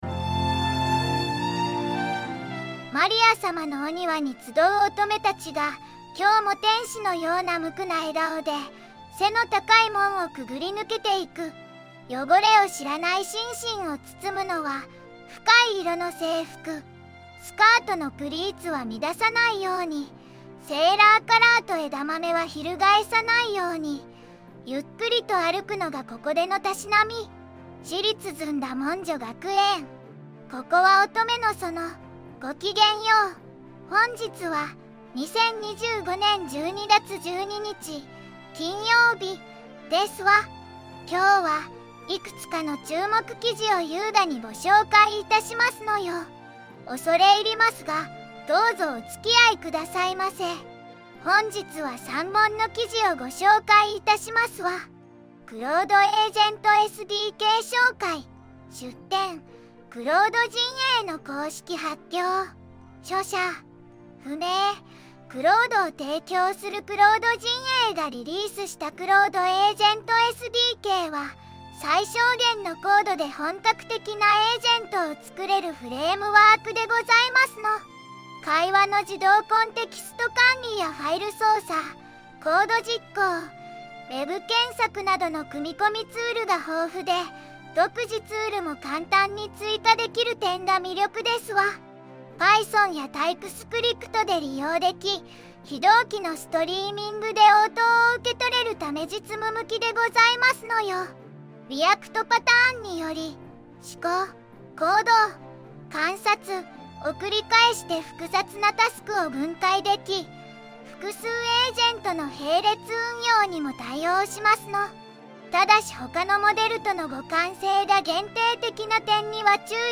VOICEVOX:ずんだもん